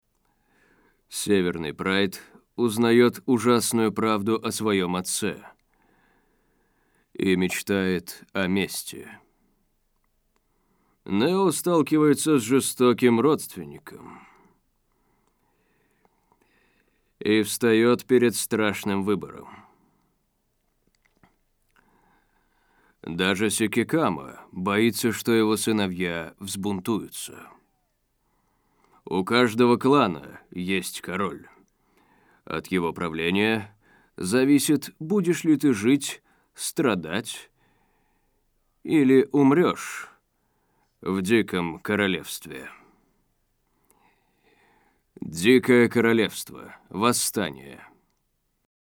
Тракт: Профессиональная студия
Демо-запись №2 Скачать